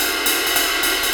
Jazz Swing #2 105 BPM.wav